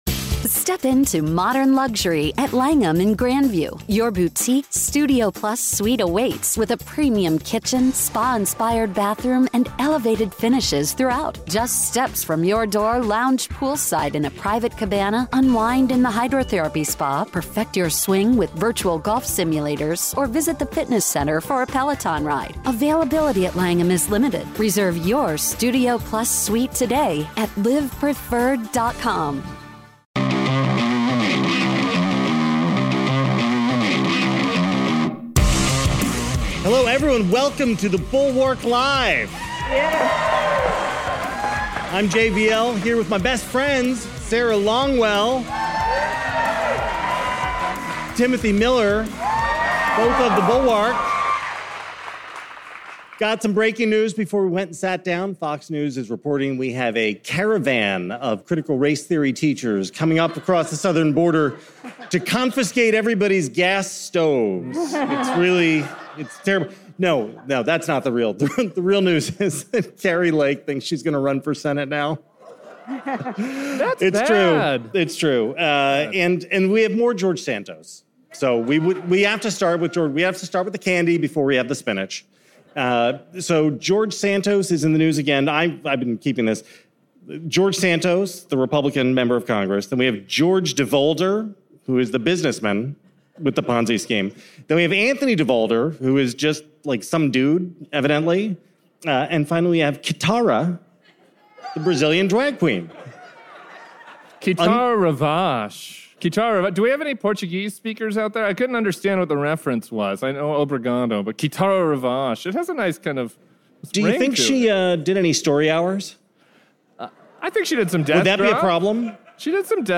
The gang hit the road this week with a live show from the Avalon Hollywood.